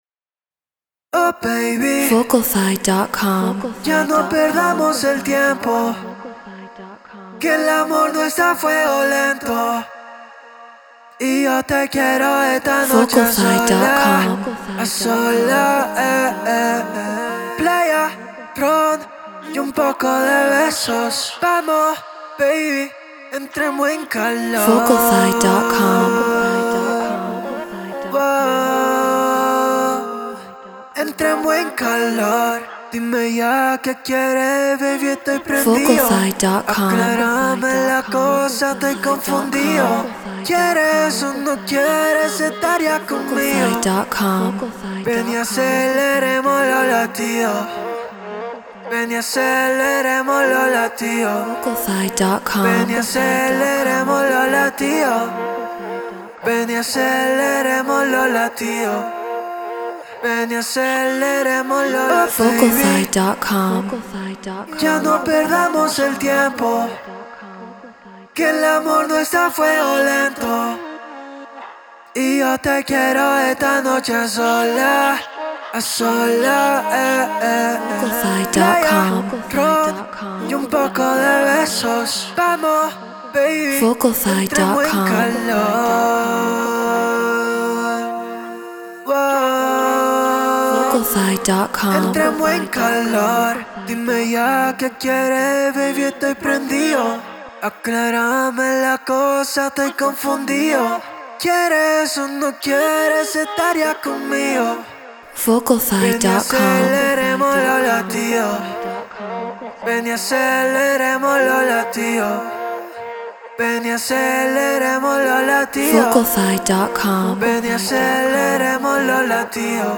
Get Royalty Free Vocals.